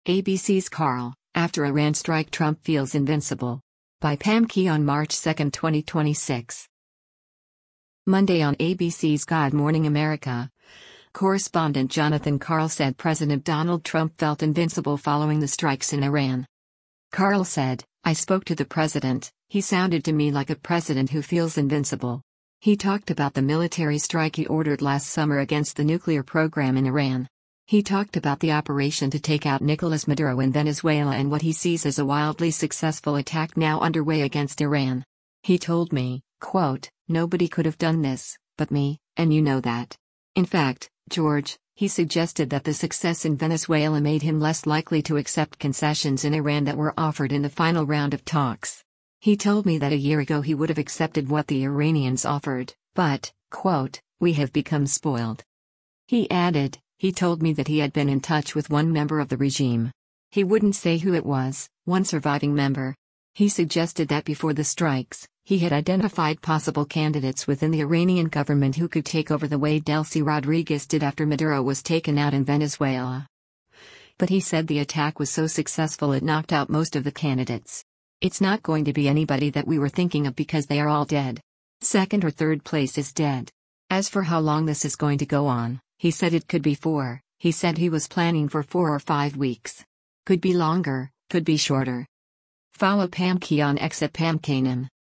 Monday on ABC’s “God Morning America,” correspondent Jonathan Karl said President Donald Trump felt “invincible” following the strikes in Iran.